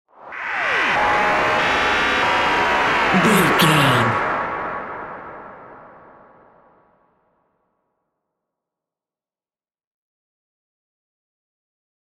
Atonal
scary
tension
ominous
dark
suspense
haunting
eerie
synth
keyboards
ambience
pads
eletronic